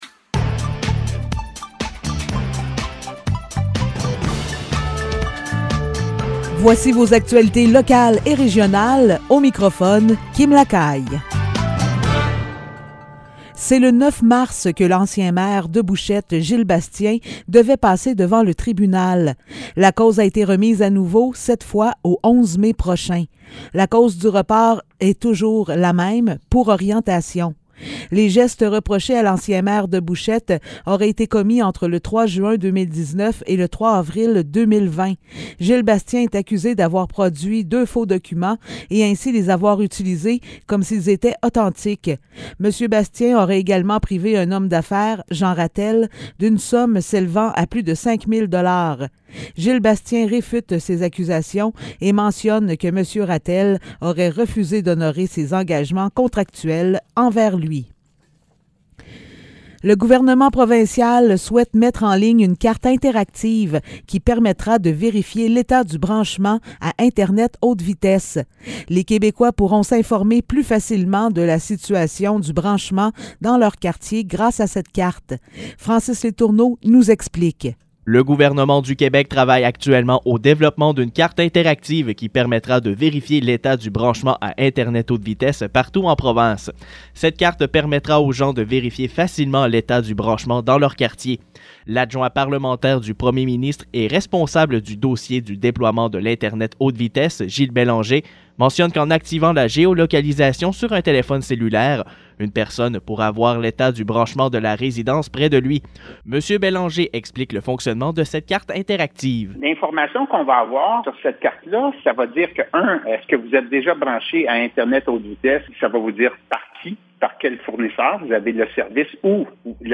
Nouvelles locales - 10 mars 2022 - 15 h